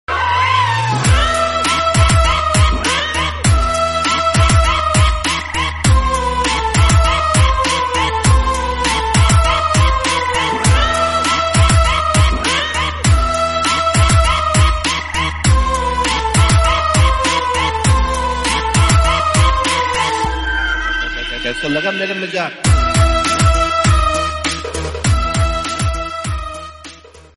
BGM Instrumental Ringtone